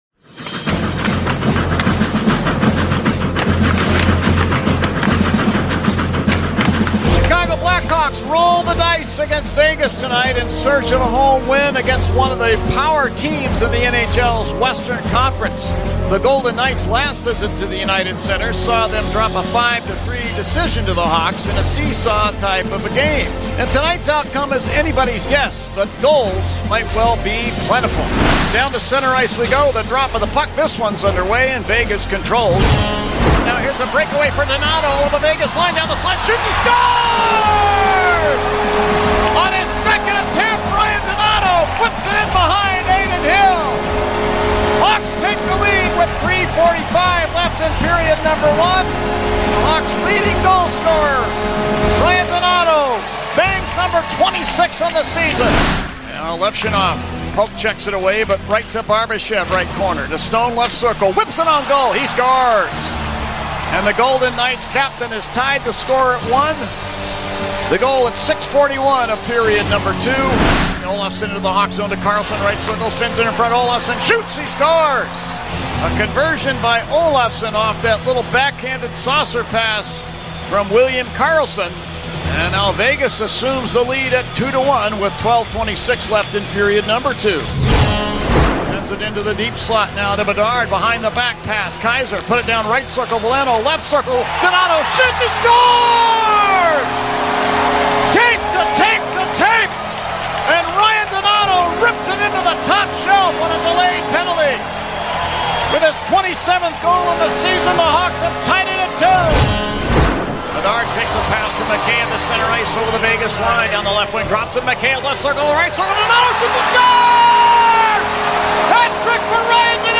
Chicago Blackhawks Highlights from 720 WGN Radio